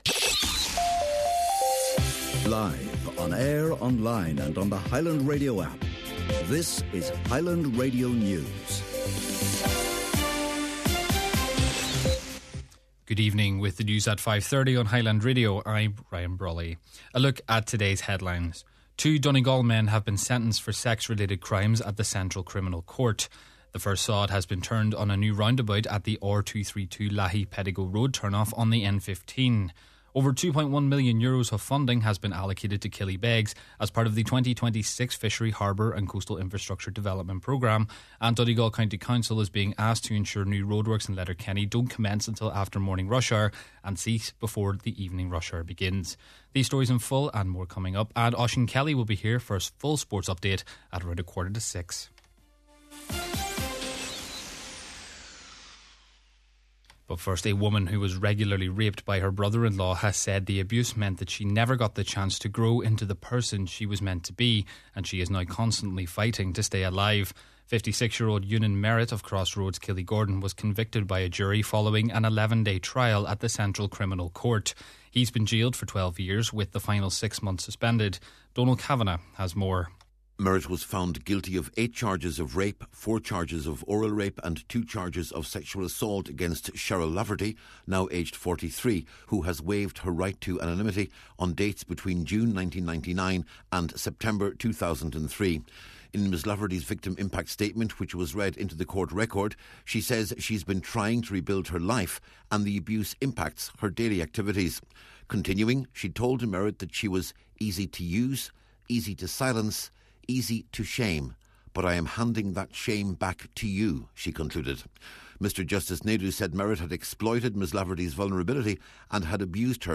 Main Evening News, Sport and Obituary Notices – Monday, February 16th